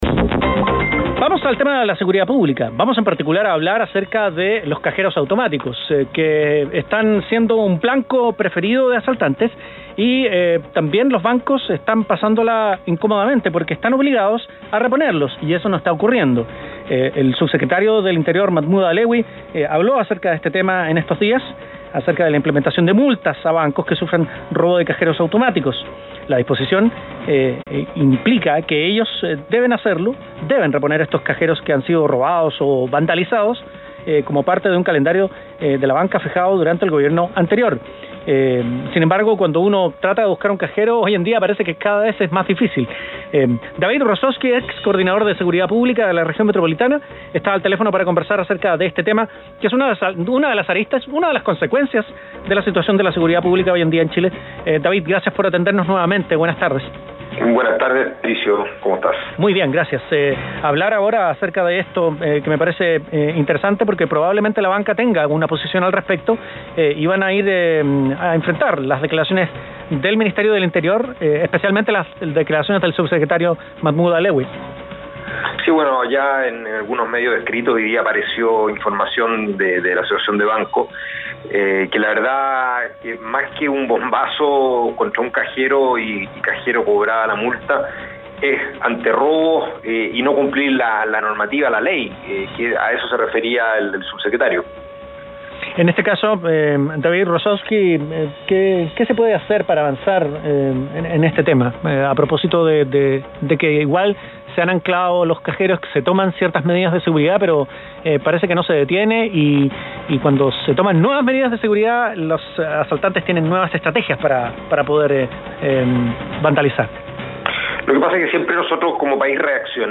Entrevista a experto en Seguridad Pública